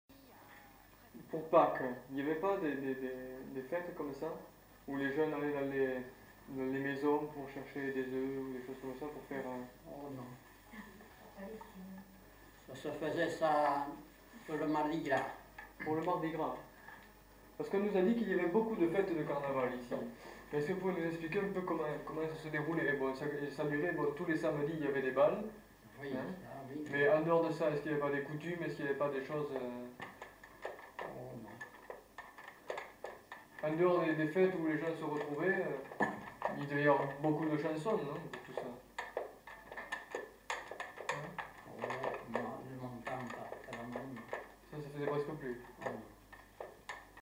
Aire culturelle : Petites-Landes
Lieu : Lencouacq
Genre : témoignage thématique